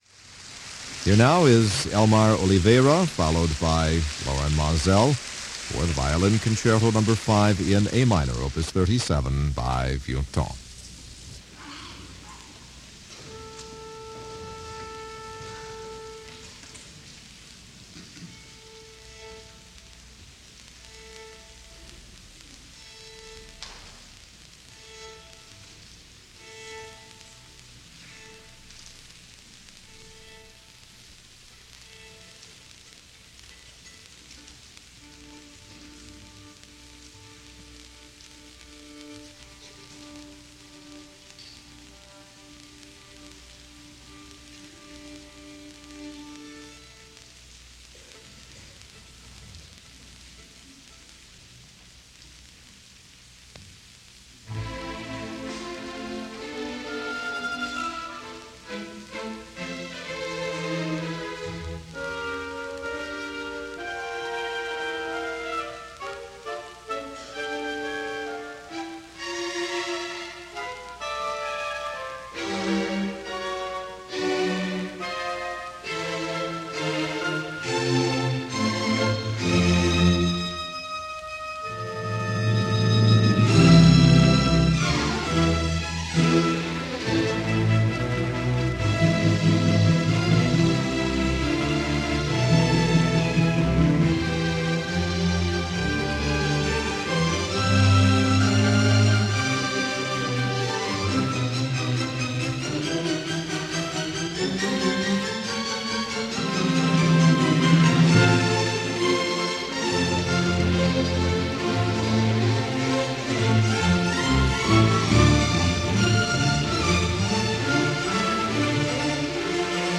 Elmar Oliveira, Violin
Lorin Maazel, Cond
Another historic concert this week.
A short concert this time, a matinee originally broadcast on May 11, 1978 (not the actual performance date) with Oliveira and Maazel playing the Violin Concerto Number 5 by Henri Vieuxtemps.
Principal violist